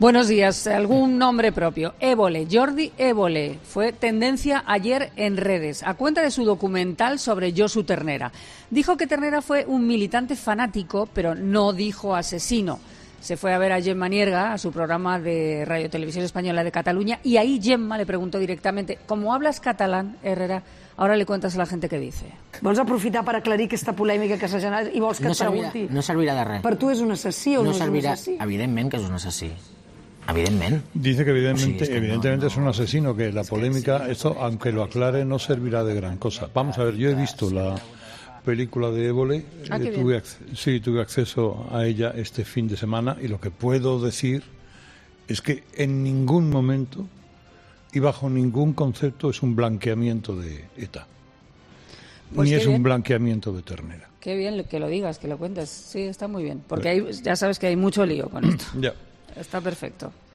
Herrera, sorprendido, ha escuchado atentamente las palabras que el periodista de Atresmedia le ha dedicado.